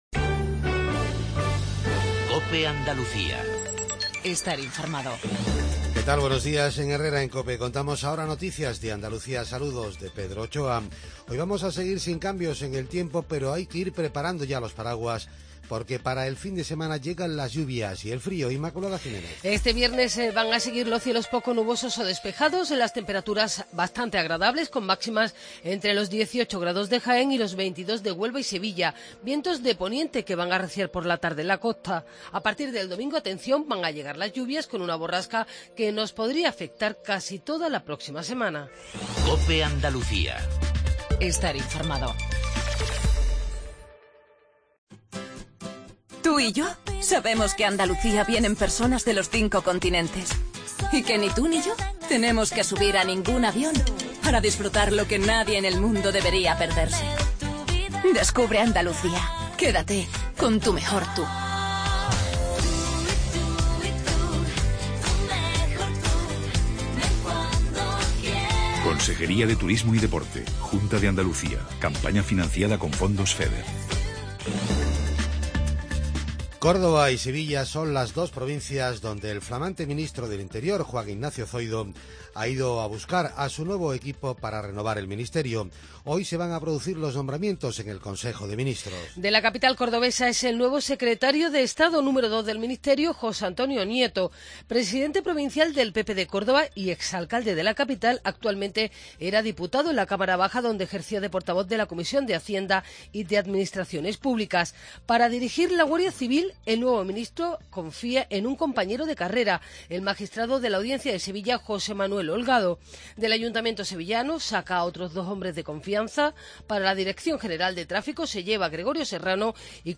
INFORMATIVO REGIONAL/LOCAL MATINAL 7:20